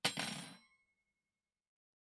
餐具的声音 " 小勺子2
Tag: 餐具